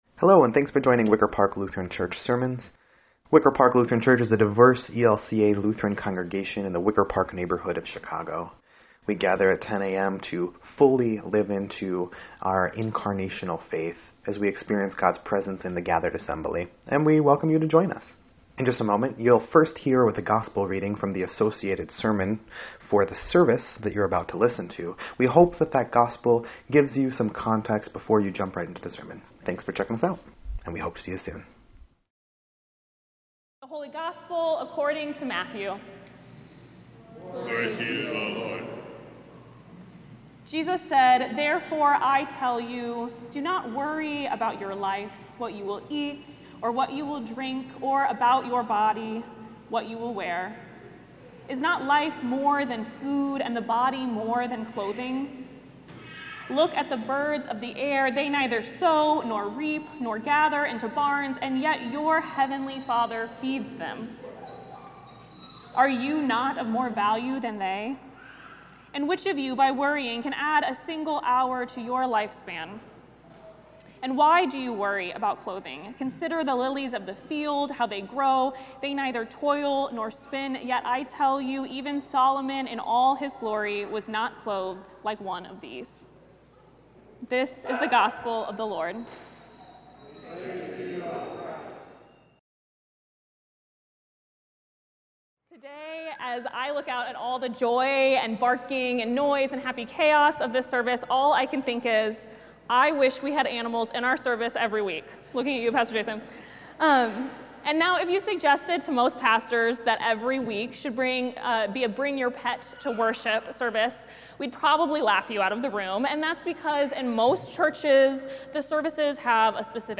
10.5.25-Sermon_EDIT.mp3